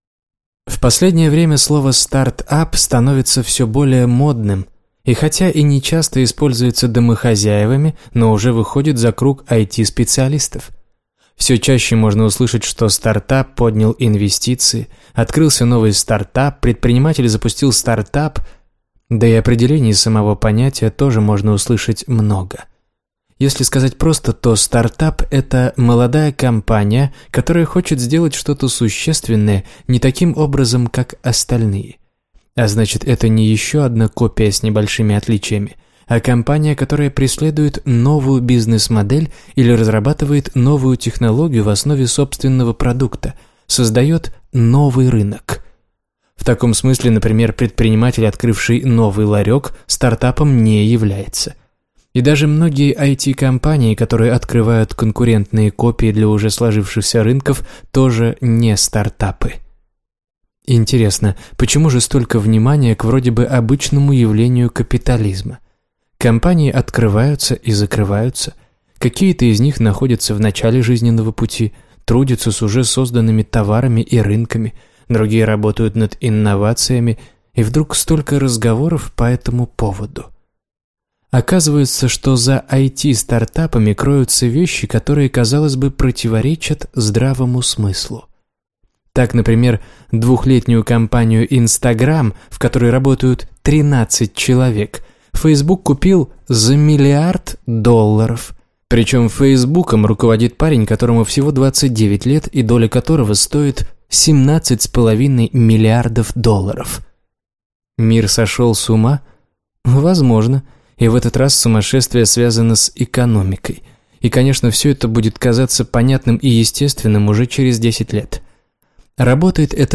Аудиокнига Стартап. Руководство для начинающих | Библиотека аудиокниг